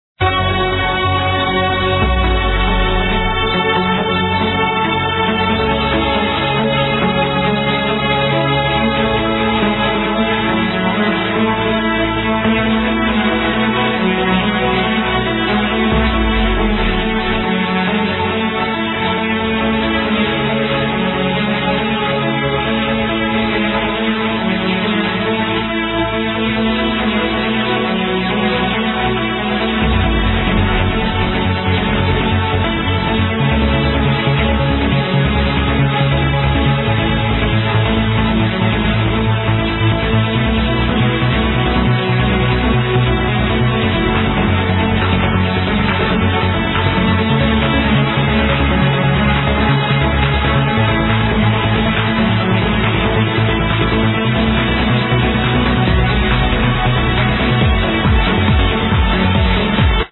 from a pulser set.
way too LQ for me to recognize